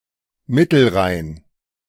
Middle Rhine (German: Mittelrhein, pronounced [ˈmɪtl̩ˌʁaɪn]